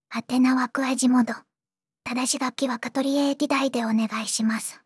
voicevox-voice-corpus
voicevox-voice-corpus / ROHAN-corpus /ずんだもん_ヒソヒソ /ROHAN4600_0014.wav